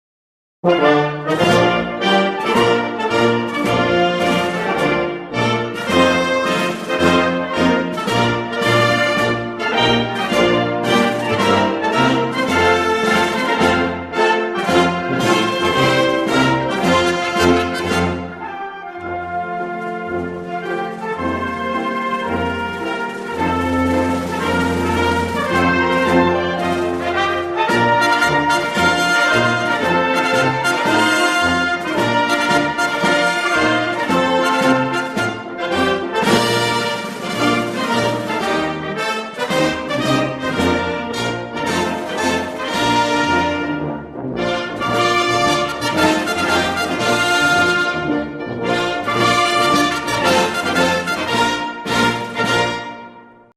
Belgian_anthem.mp3